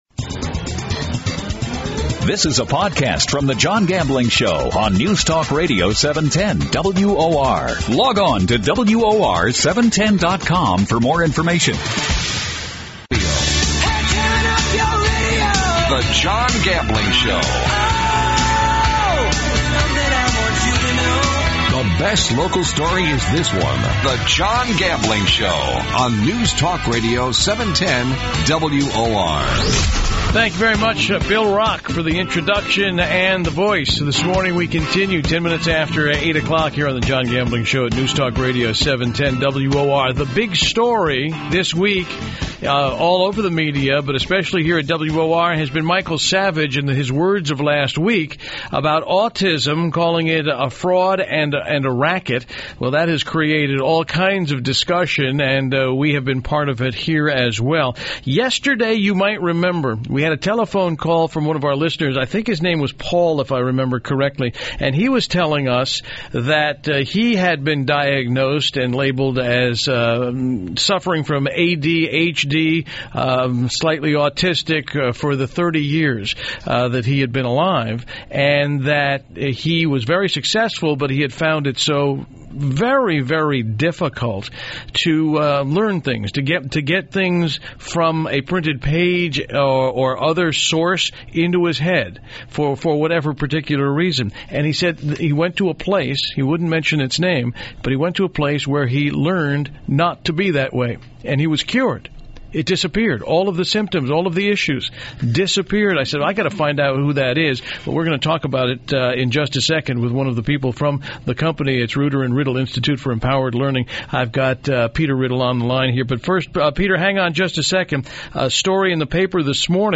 John Gambling Interview